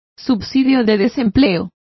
Complete with pronunciation of the translation of doles.